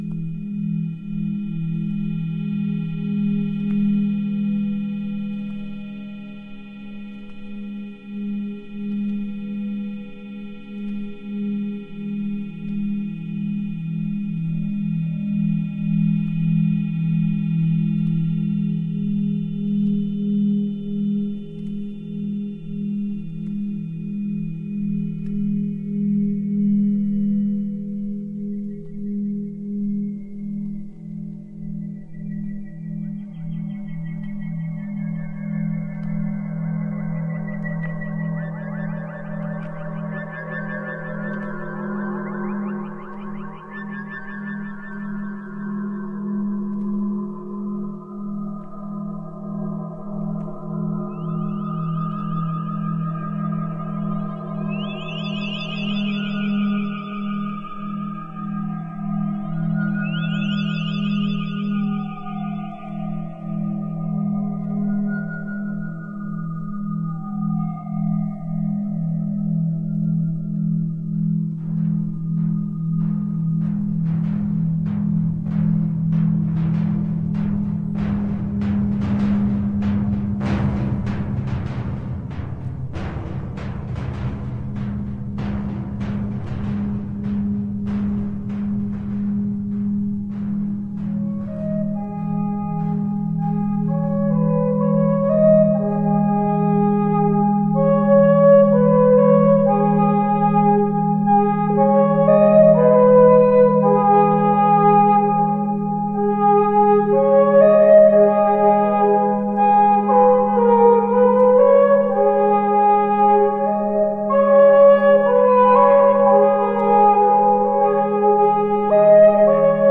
A wonderful set of dovetailing cosmic pieces
Finnish Experimental electroacoustic artists
at the Experimental Studio of Yleisradio
• Genre: Experimental / Electroacoustic